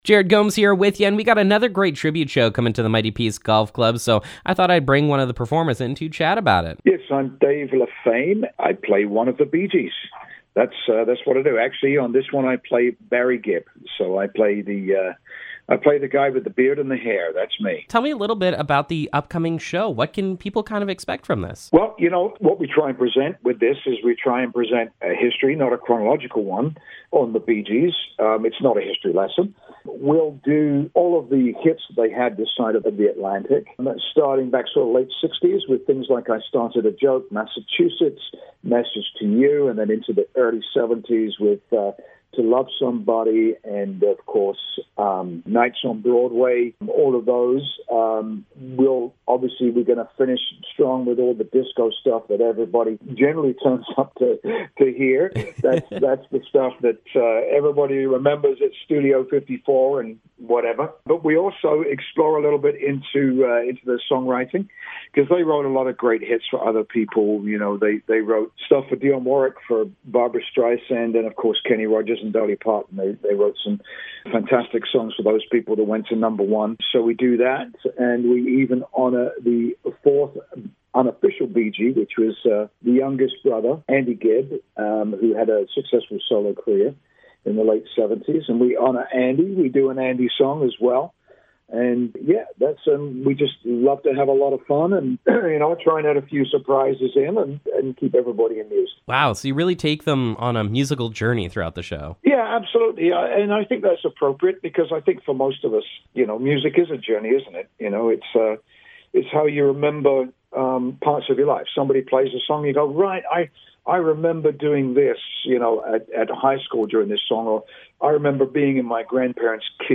Bee gees Tribute show Interview